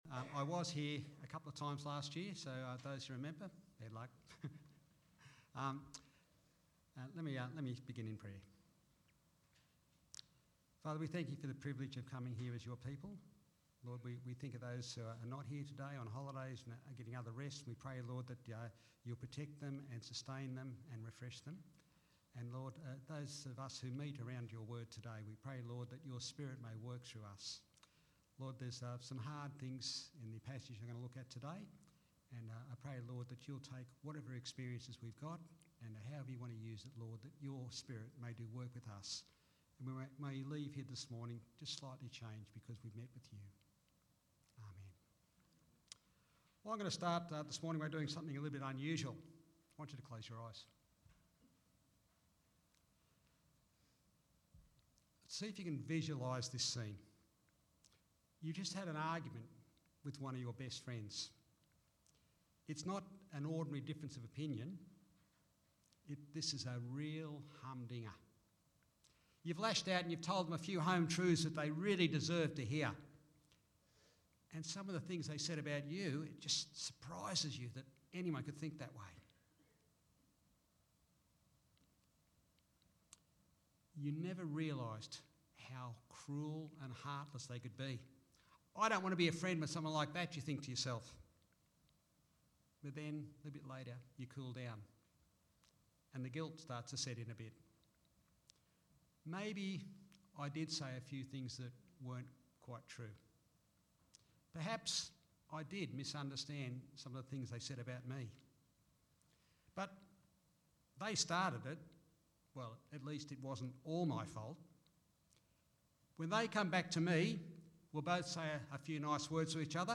Sermons – Oak Flats Anglican